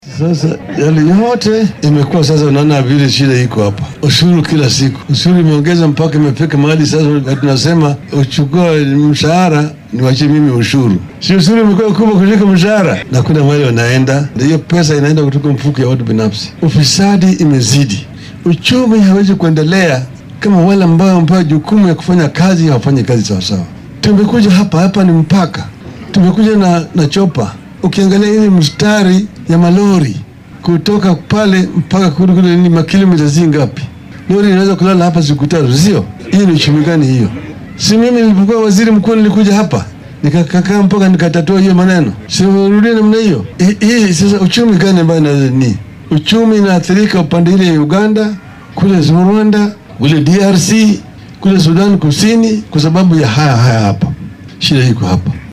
Waxaa uu xusay in hoos u dhaca dhaqaale ee wadanka laga dareemaya ay tahay hoggaan xumo, madaama dowladda dhexe ay ururisay canshuur dheeraad ah. Raila Odinga ayaa hadalkan jeediyay xilli uu maanta la kulmay xubnaha xisbiga ODM ee ismaamulka Busia.